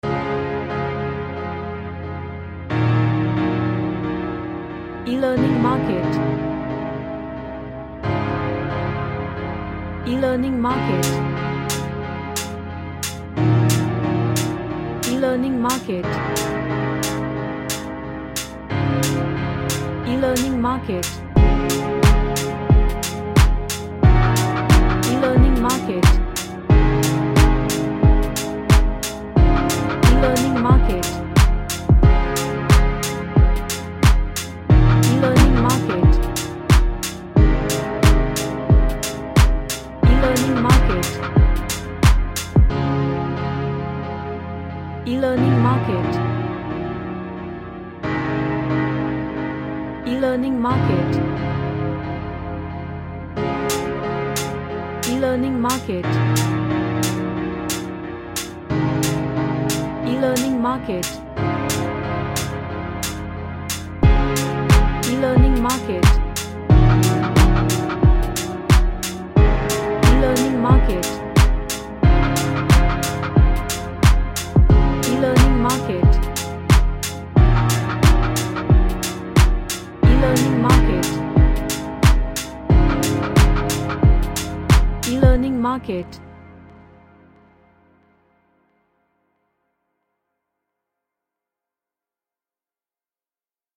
A simple track iwth chords and drums to it.
Happy